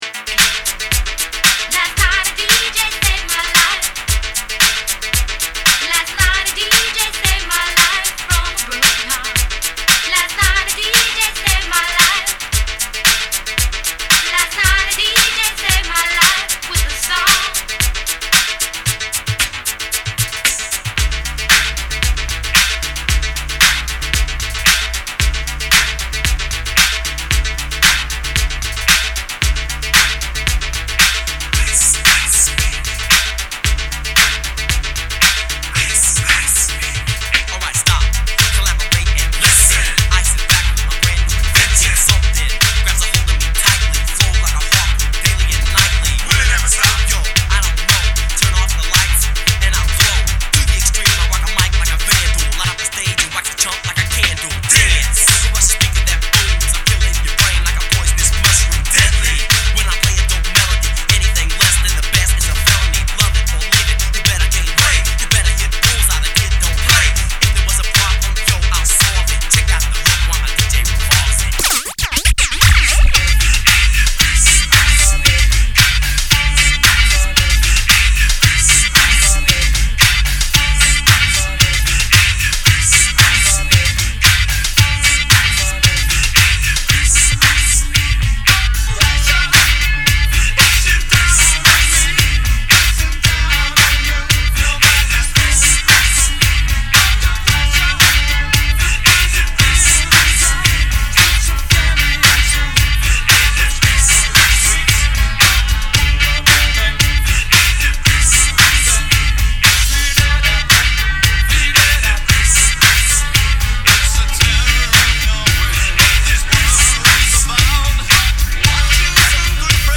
Mashups Mash-up Remix